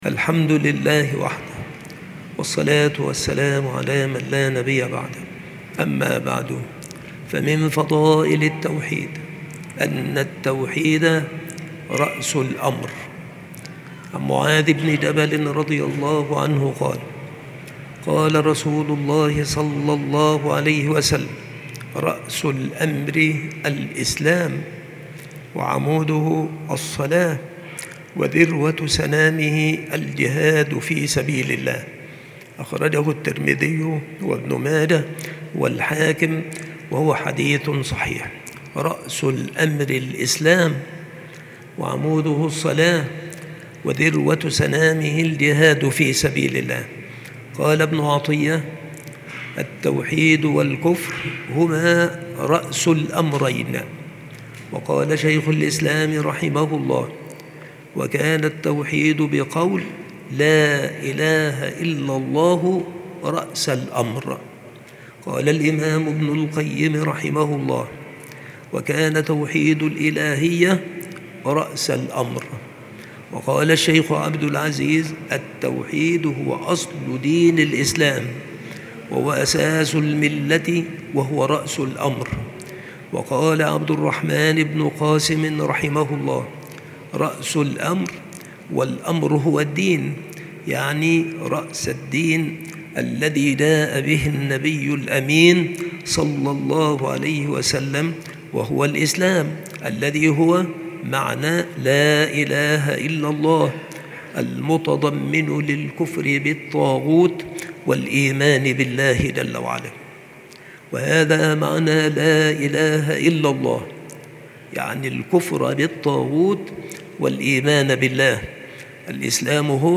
بالمسجد الشرقي - سبك الأحد - أشمون - محافظة المنوفية - مصر